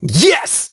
hotshot_kill_07.ogg